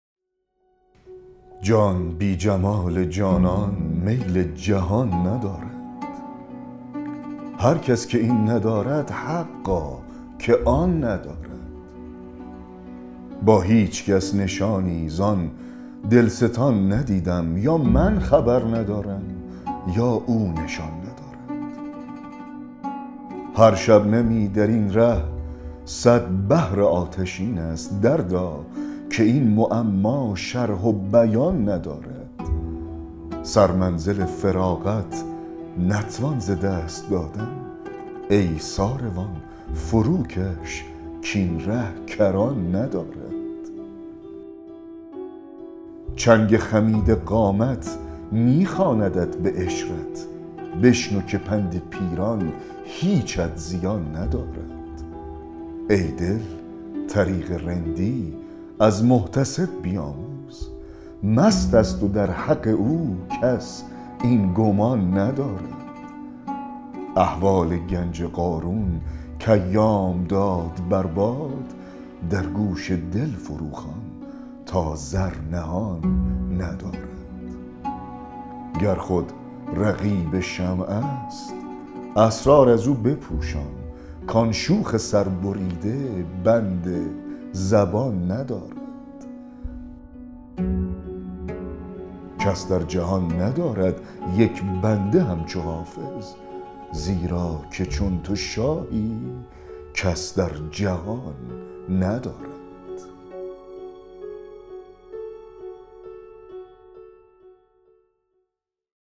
دکلمه غزل 126 حافظ
دکلمه-غزل-126-حافظ-جان-بی-جمال-جانان-میل-جهان-ندارد.mp3